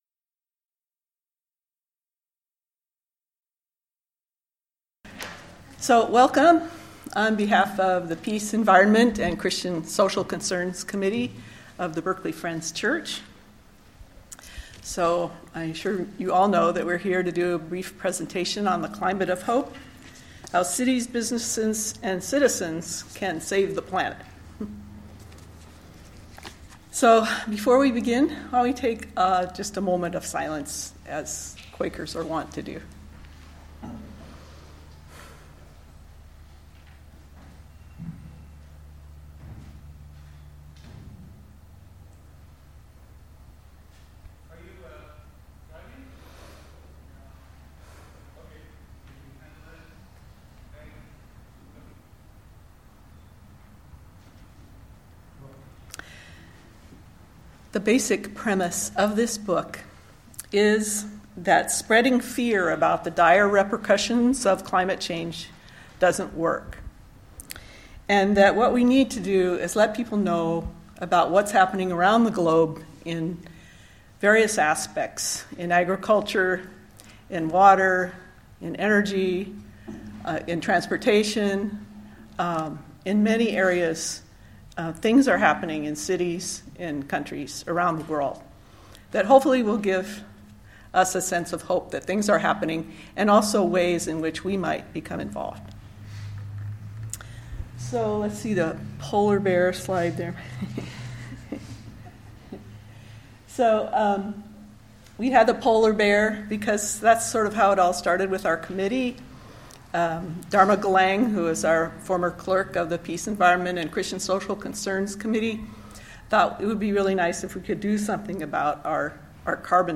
This event, a presentation and discussion of the book Climate of Hope, by Michael Bloomberg and Carl Pope, was held on February 25, 2018, at Berkeley Friends Church.
Listen to the presentation and discussion.